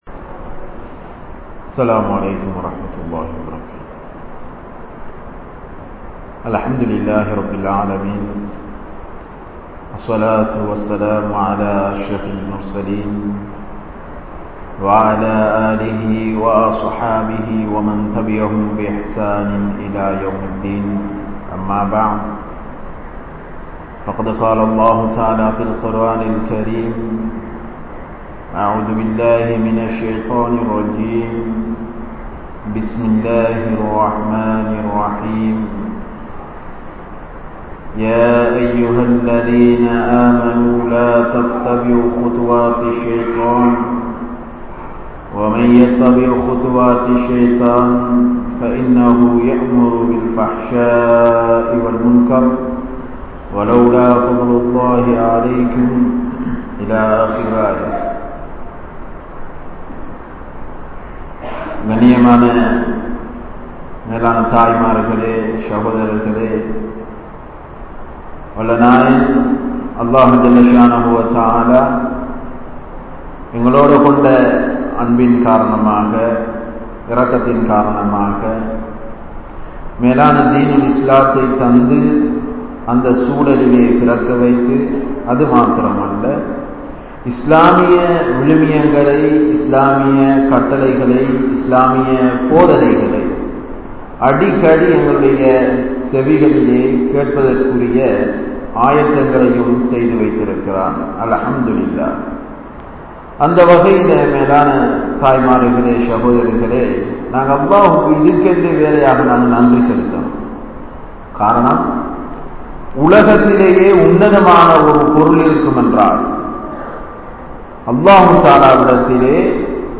Pengal Elloarudanum Peasa Mudiuma?? (பெண்கள் எல்லோருடனும் பேச முடியுமா??)(Part 01) | Audio Bayans | All Ceylon Muslim Youth Community | Addalaichenai